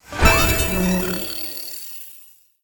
LevelComplete.wav